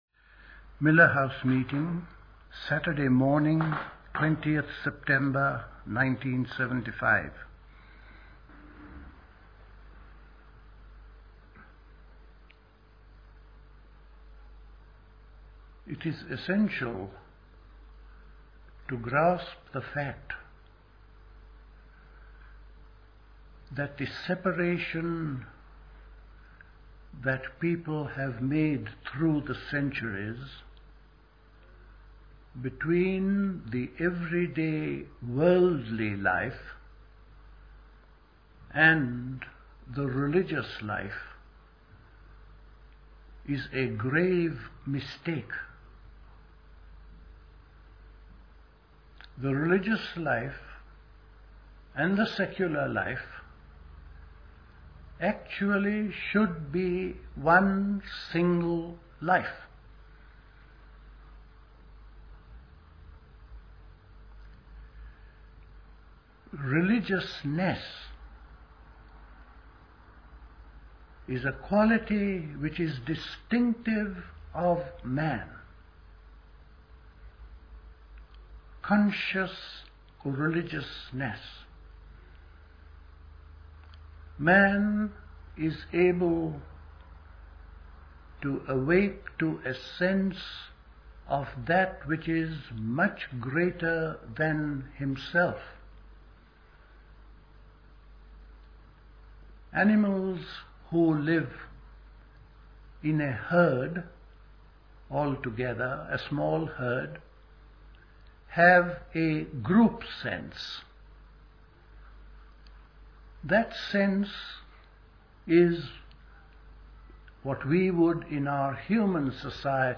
Recorded at the 1975 Elmau Autumn School.